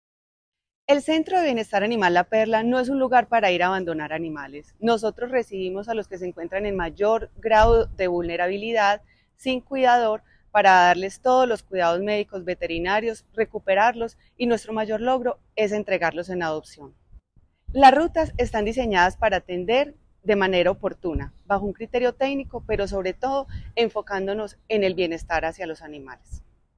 Audio Declaraciones de la secretaria (e) de Medio Ambiente, Elizabeth Coral La Alcaldía de Medellín refuerza su compromiso con el bienestar animal mediante la activación y divulgación de varias rutas oficiales de atención frente al maltrato, abandono o tenencia inadecuada.
Audio-Declaraciones-de-la-secretaria-e-de-Medio-Ambiente-Elizabeth-Coral-2.mp3